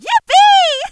shelly_kill_03.wav